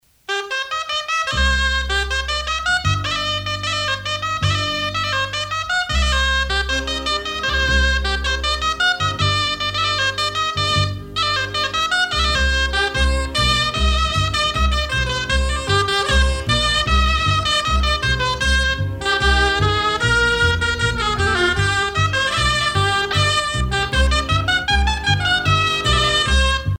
les hautbois
Pièce musicale éditée